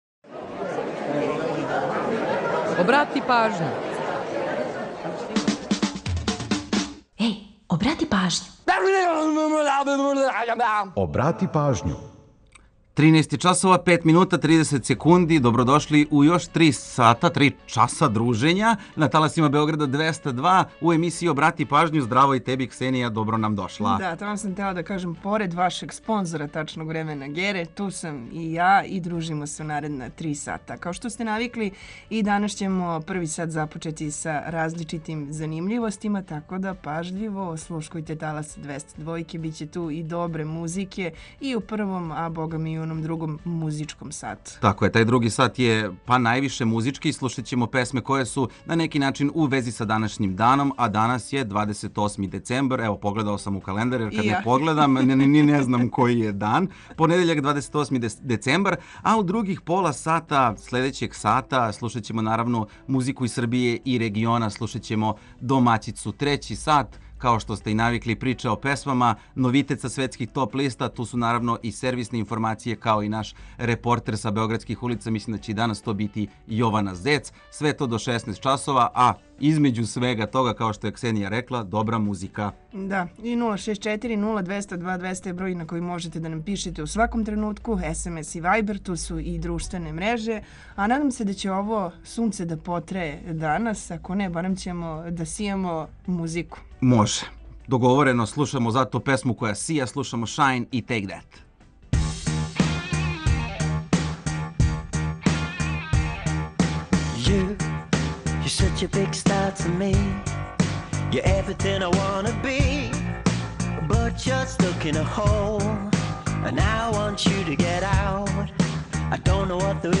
Ту је и пола сата резервисано само за музику из Србије и региона, а упућујемо вас и на нумере које су актуелне. Чућете и каква се то посебна прича крије иза једне песме, а за организовање дана, ту су сервисни подаци и наш репортер.